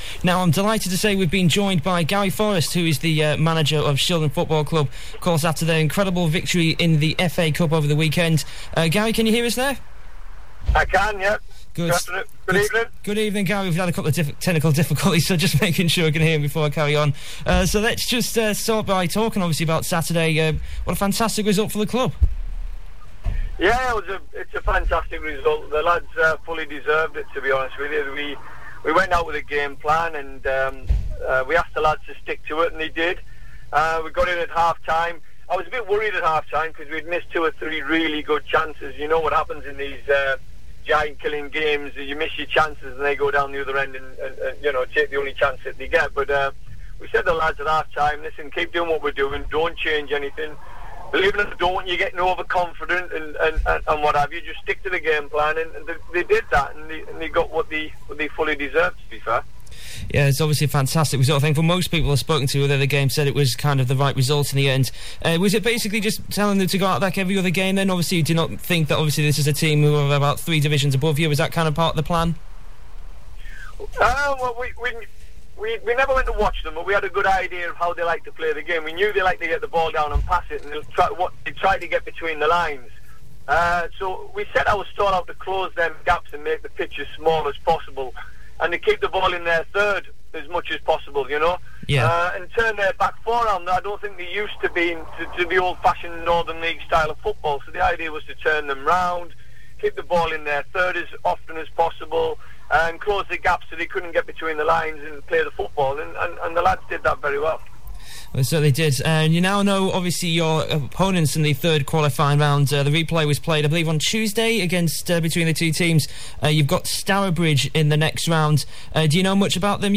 intervew